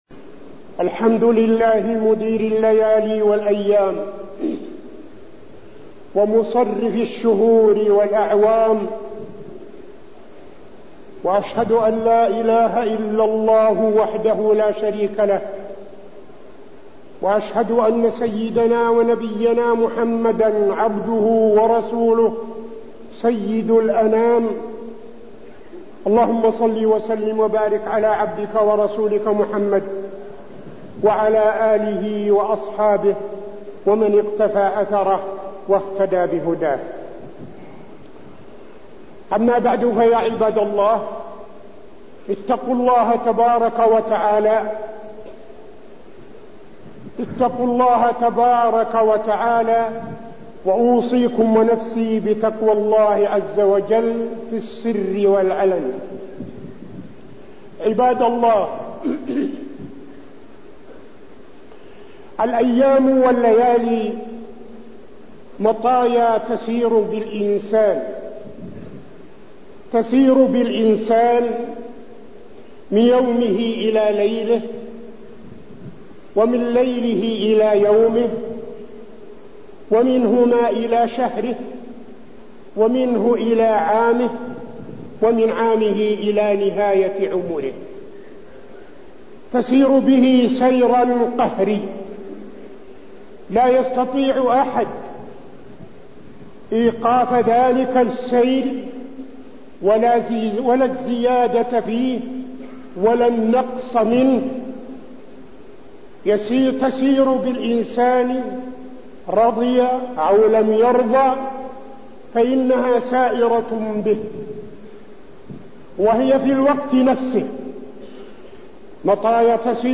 تاريخ النشر ٢٨ ذو الحجة ١٤٠٥ هـ المكان: المسجد النبوي الشيخ: فضيلة الشيخ عبدالعزيز بن صالح فضيلة الشيخ عبدالعزيز بن صالح الهجرة النبوية والعام الجديد